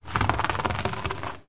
Звук катящегося по поверхности ананаса